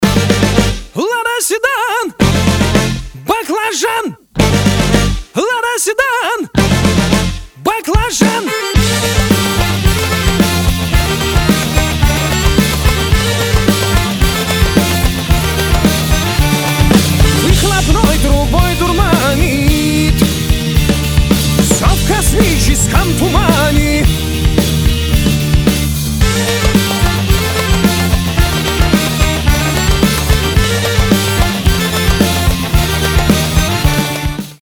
• Качество: 320, Stereo
рок